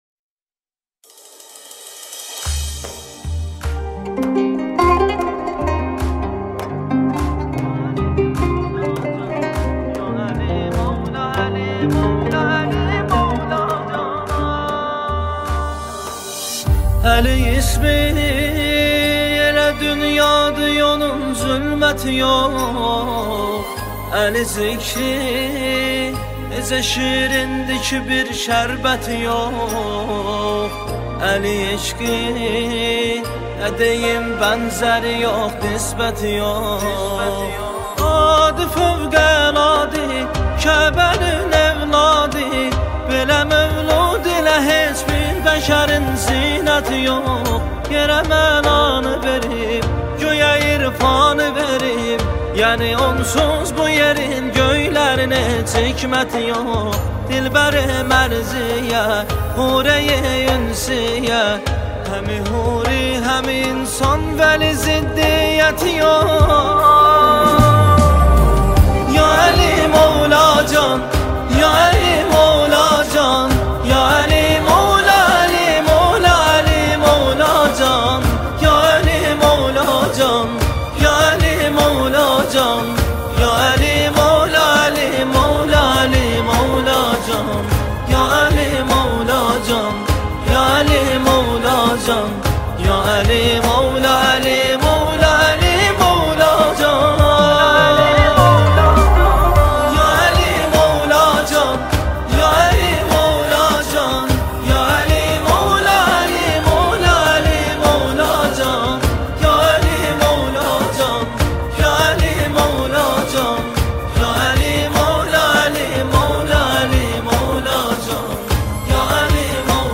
نماهنگ دلنشین آذری